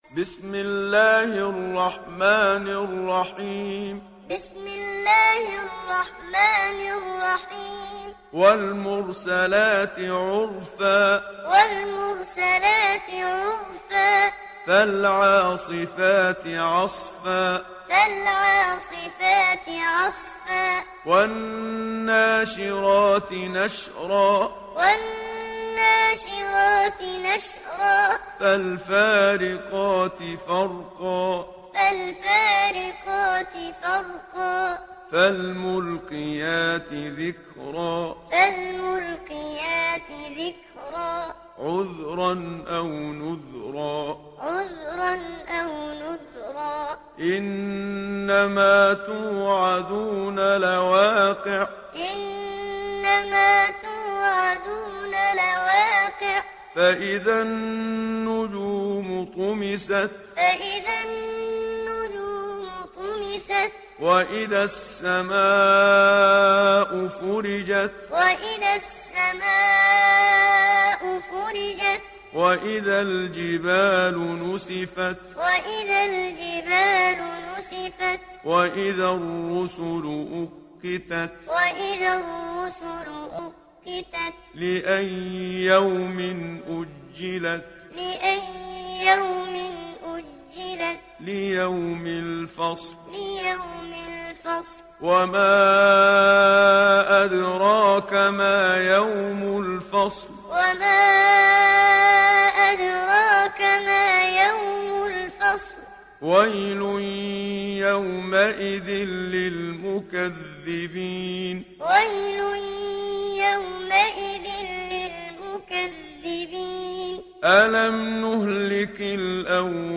İndir Mürselat Suresi Muhammad Siddiq Minshawi Muallim